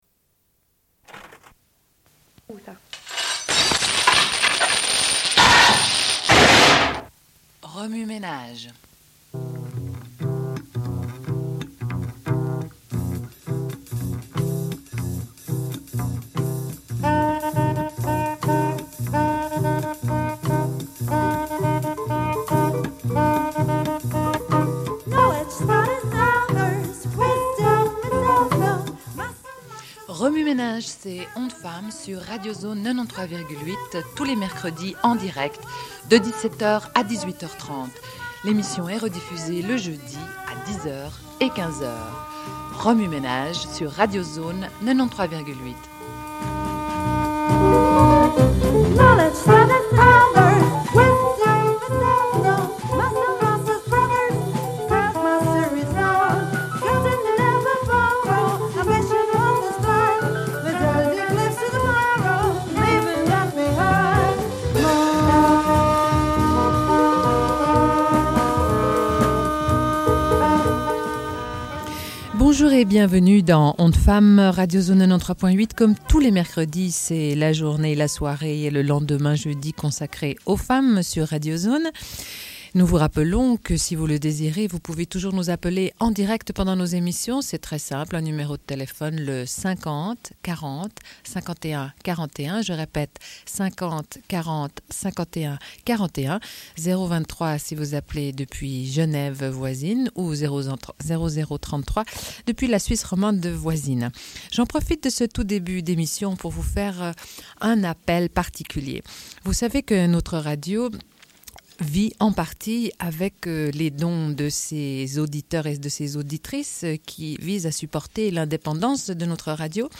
Une cassette audio, face A31:18